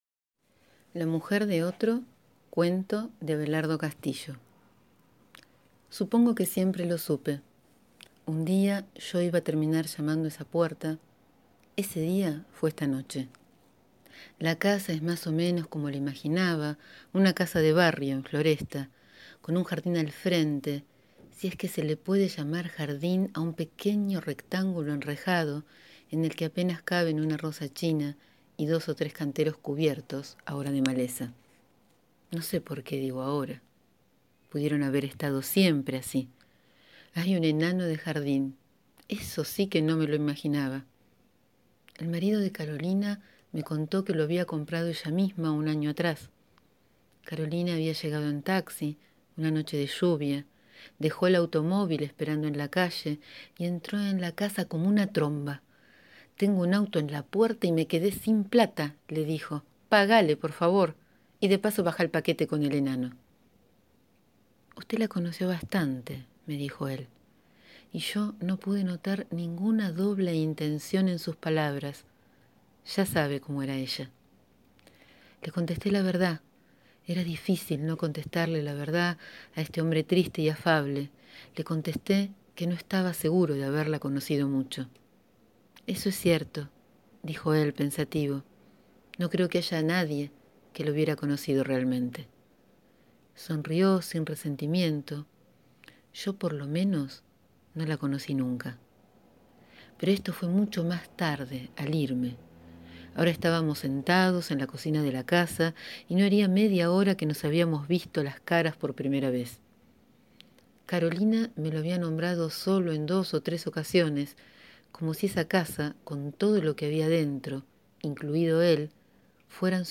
Hoy leo este gran cuento de Abelardo Castillo (1935-2017): «La mujer del otro».